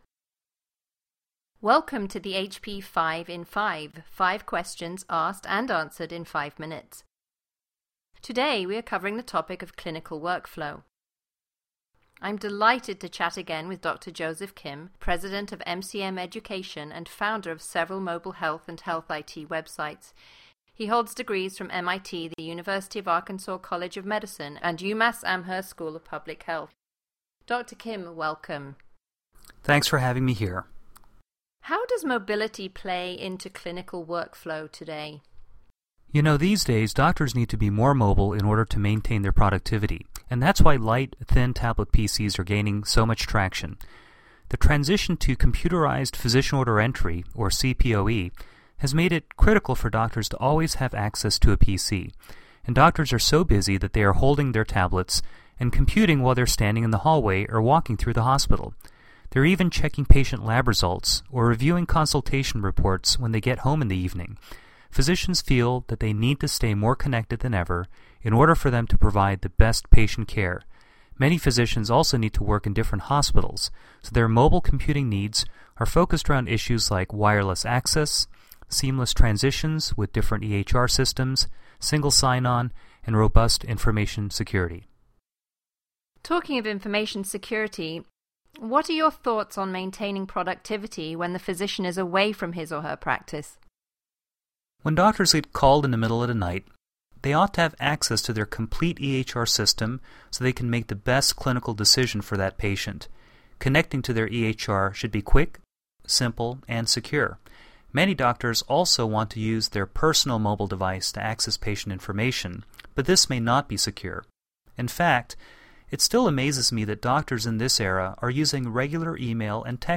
5in5 recordings are sponsored by HP, however opinions on products and services expressed are those of the health-care professional being interviewed.